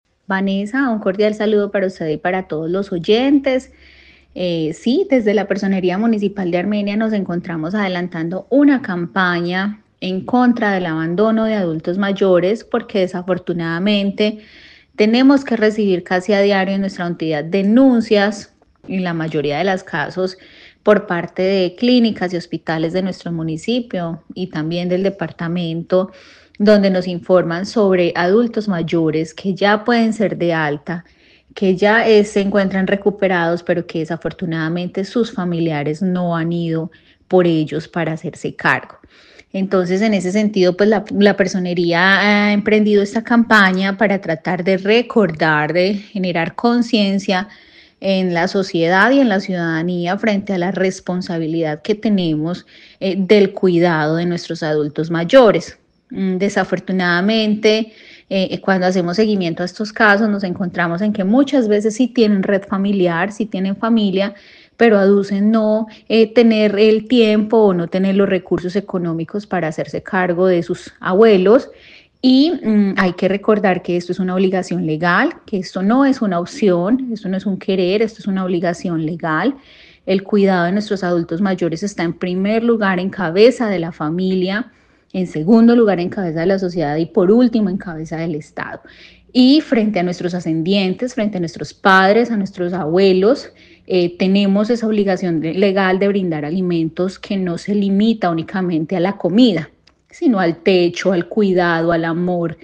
Personera de Armenia, Juliana Victoria Ríos